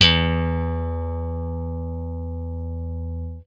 FENDRPLUCKAA.wav